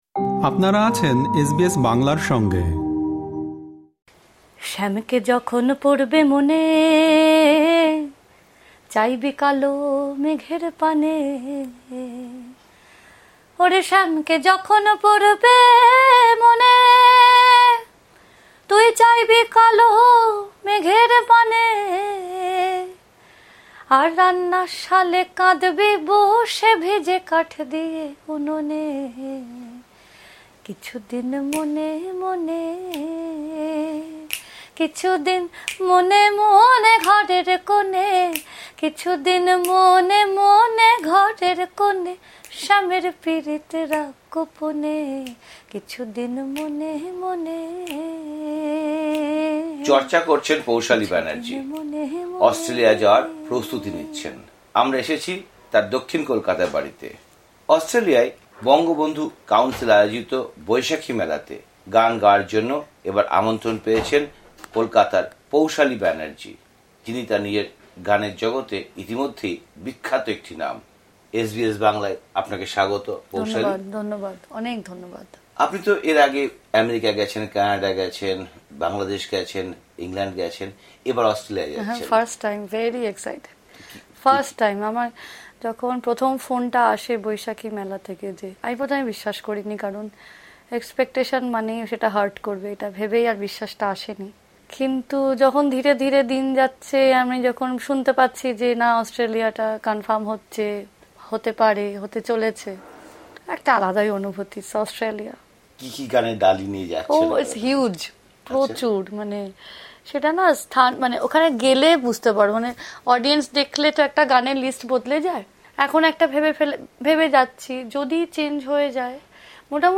সেখানে বিচারকদের একটি প্রশ্ন তার সঙ্গীতজীবনের মোড় ঘুরিয়ে দেয়, সে কথা শিল্পী নিজেই বলেছেন। তার গান এবং জীবনযাপন নিয়ে এসবিএস বাংলার সঙ্গে কথা বলেছেন এই শিল্পী।